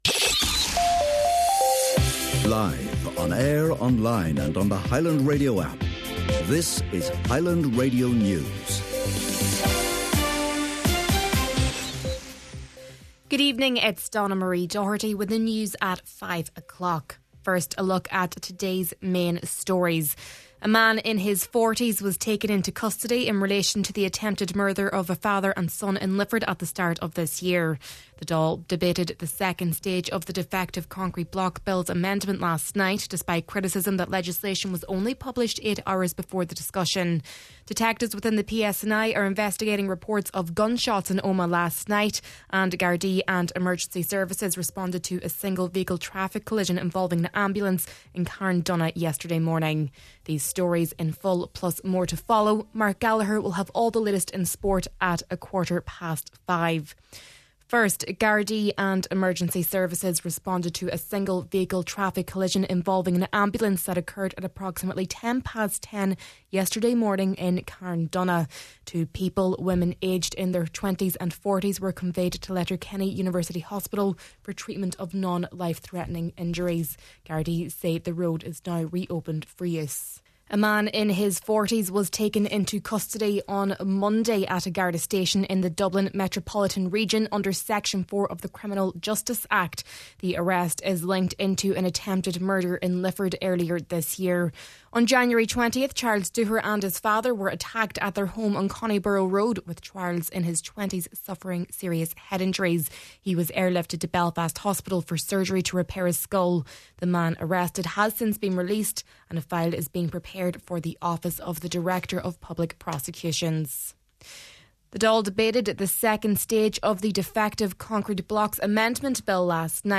Main Evening News, Sport, and Obituary Notices – Wednesday, December 3rd